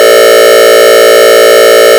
Alternating Tones
Hi, I need to generate a square wave that alternates between 5 cycles of 600hz, and 4 cycles of 435hz.